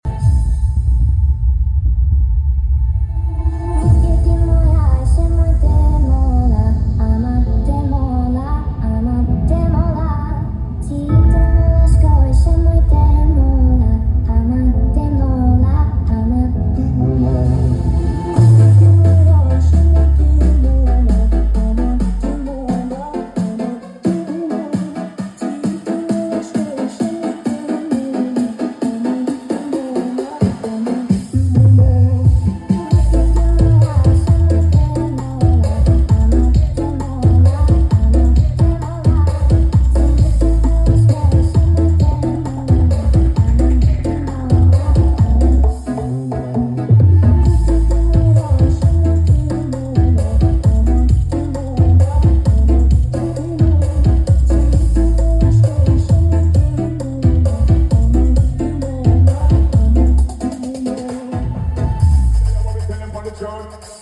CEK SOUND TJ AUDIO. Otw sound effects free download